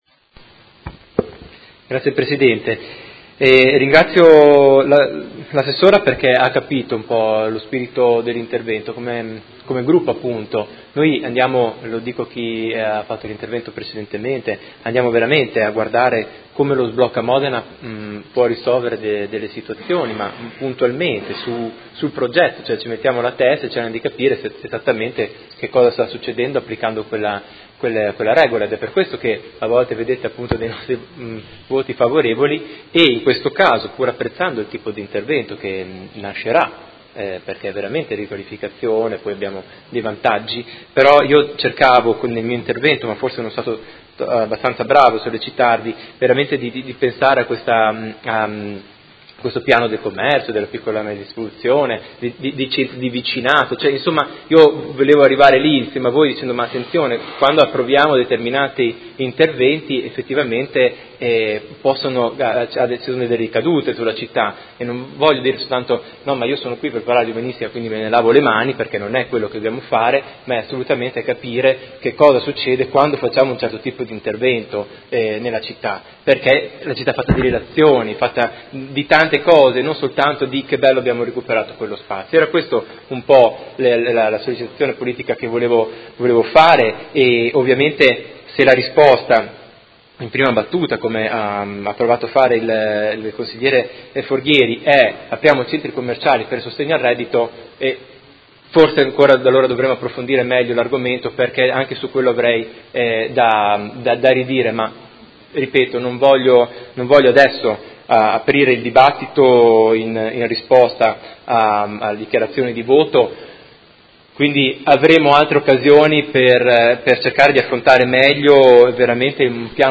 Marco Chincarini — Sito Audio Consiglio Comunale
Seduta del 13/07/2017 Dichiarazione di voto.